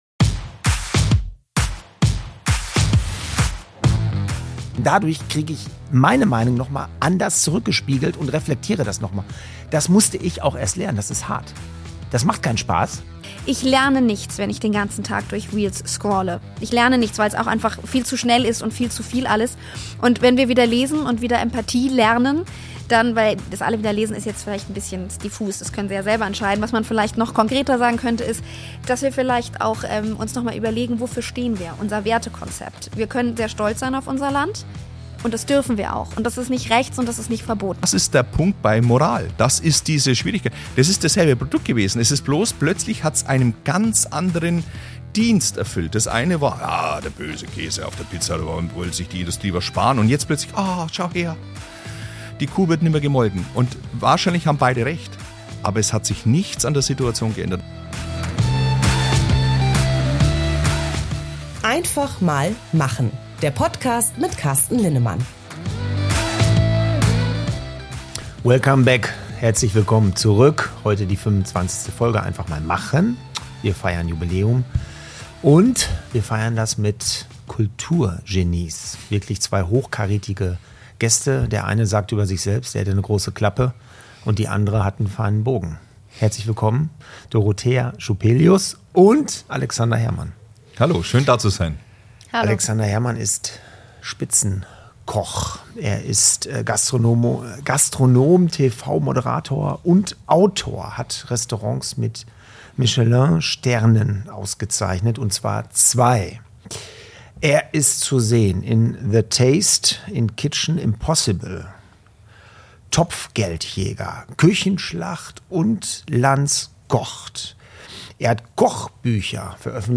Die 25te Folge von ‚Einfach mal machen‘ ist eine Jubiläumssendung.